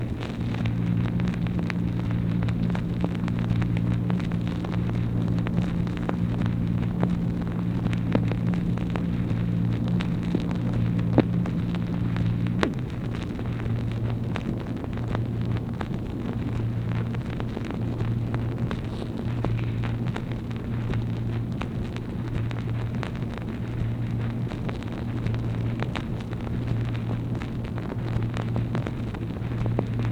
MACHINE NOISE, April 8, 1964
Secret White House Tapes | Lyndon B. Johnson Presidency